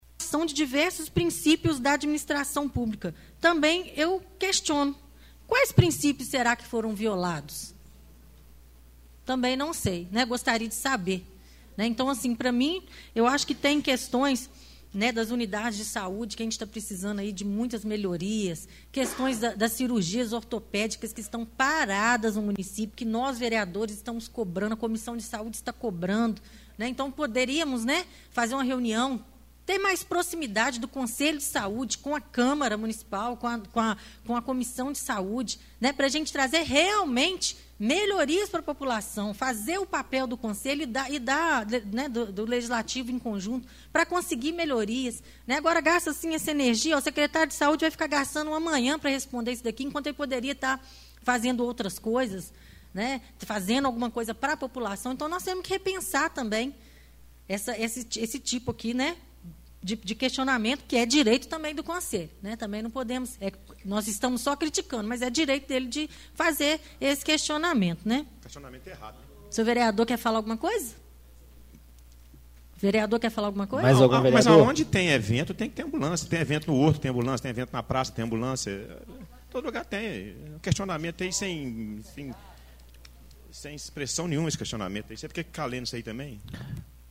Vereadora questionou o ofício do Conselho Municipal de Saúde, fez críticas e no final da sua fala, ironizou o vereador José Carlos dos Reis